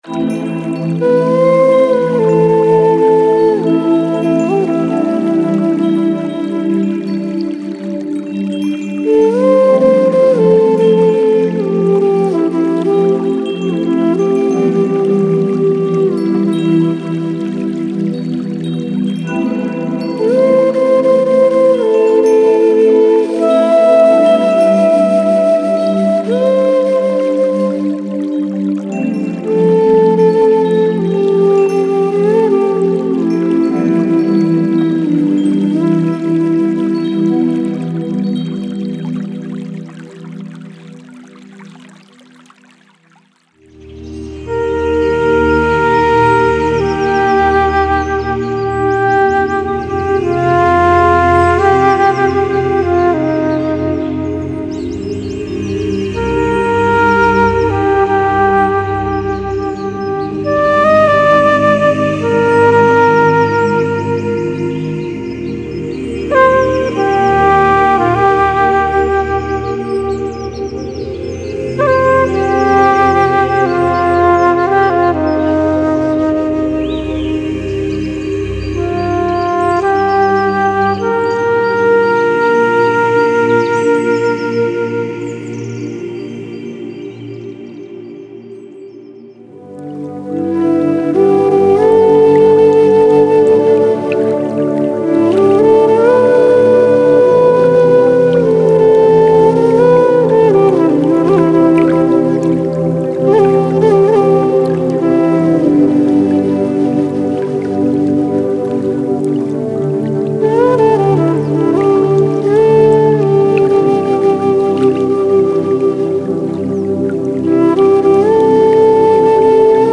Genre:Ambient
すべてのフルートは、ドライ（無加工）バージョンとウェット（リバーブ処理済み）バージョンの両方が用意されています。
デモには他のサウンドが含まれていますが、それらはあくまで使用例のためであり、本製品には含まれておりません。
76 Live Flute Loops DRY
Tempo/BPM 60-80